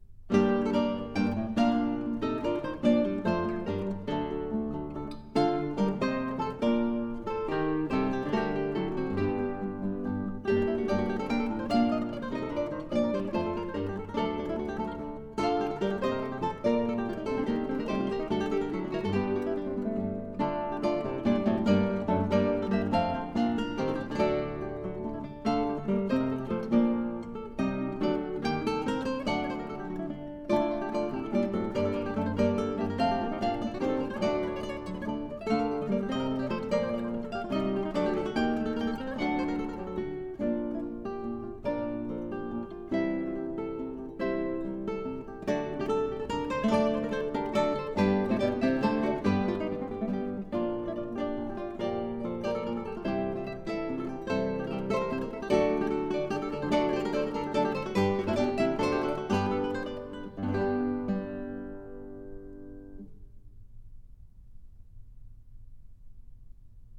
音乐类别： 吉他重奏
唱片类型： DDD